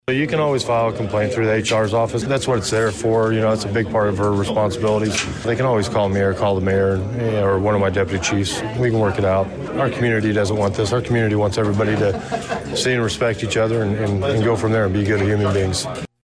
Danville Police Chief Christopher Yates addresses Danville City Council.